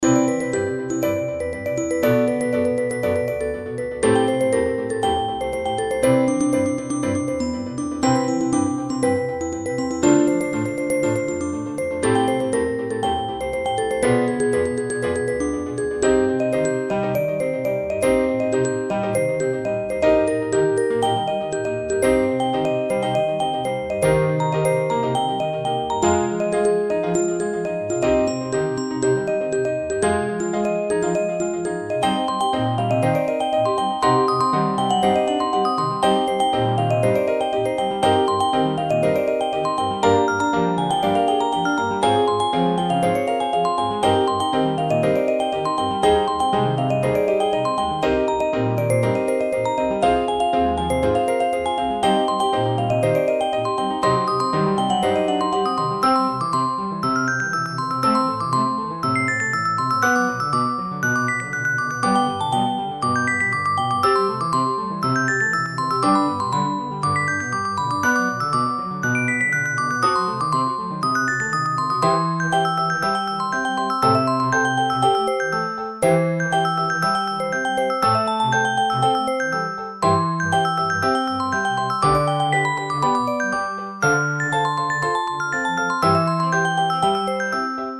ティンクルベル、ピアノ、コントラバス